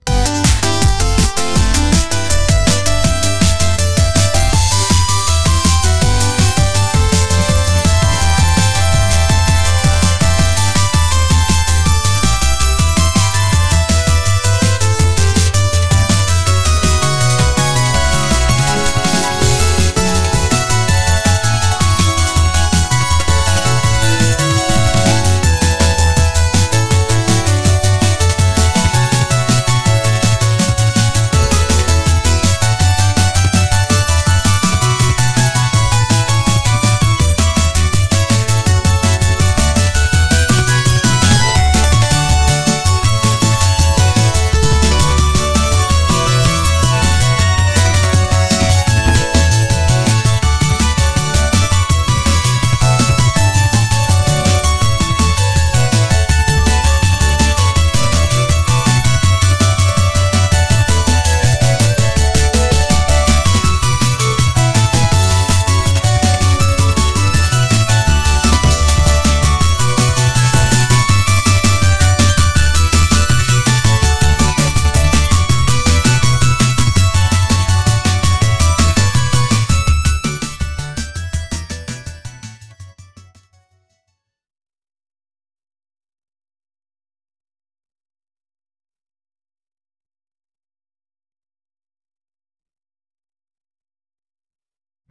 music
Christmas synths_5.wav